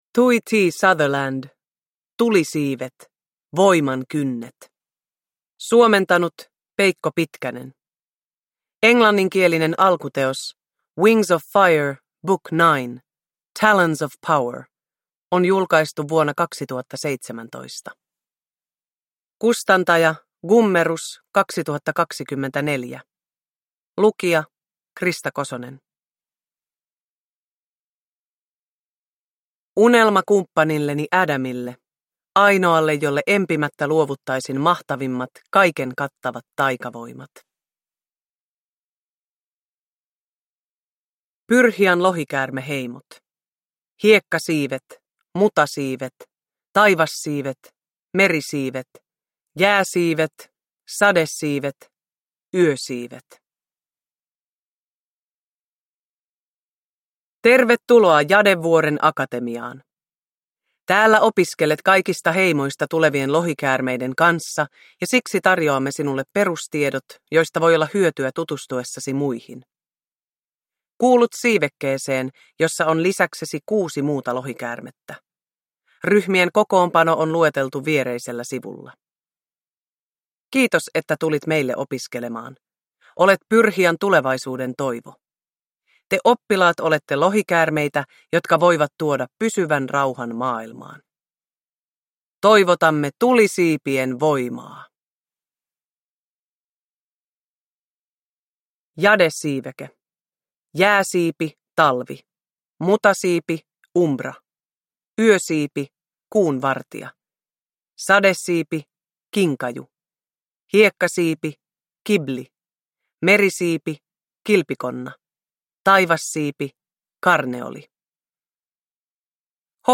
Voiman kynnet – Ljudbok
Uppläsare: Krista Kosonen